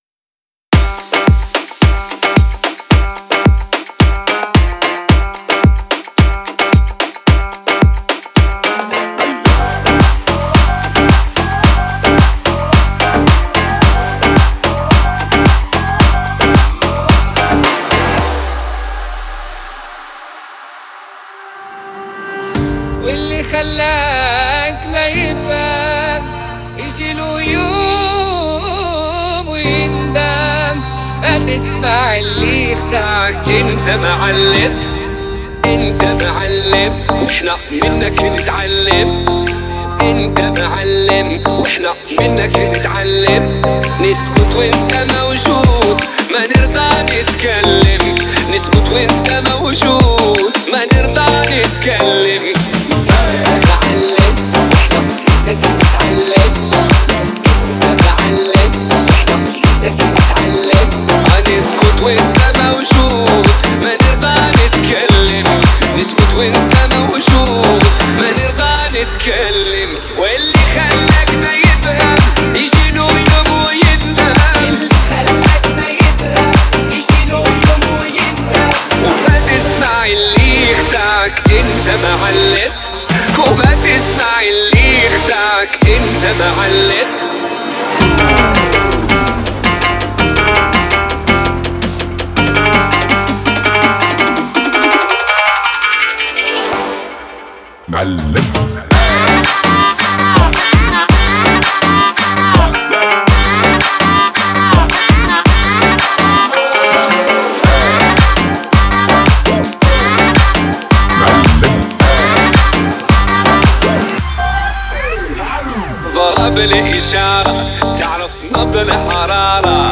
Category : Bollywood DJ Remix Songs